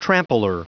Prononciation du mot trampler en anglais (fichier audio)